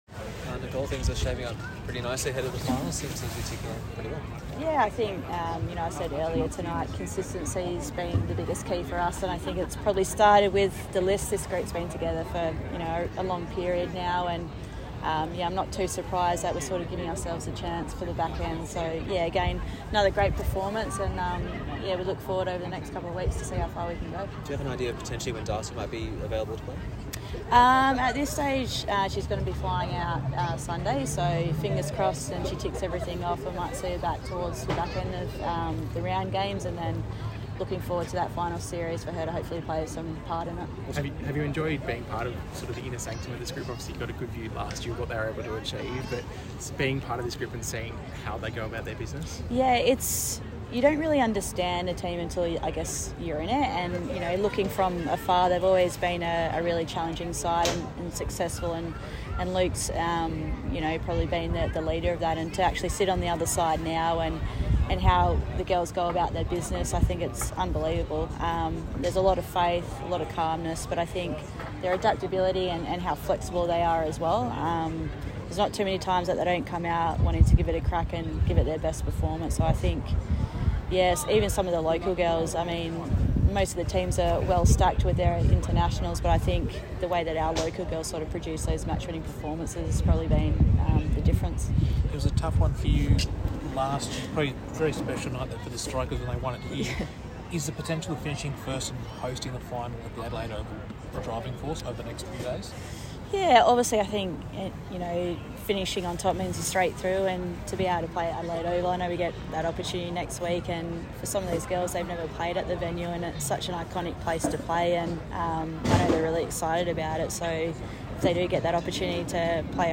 Strikers Assistant Coach Nicole Bolton speaking to media after the Strikers seven wicket win over Sydney Sixers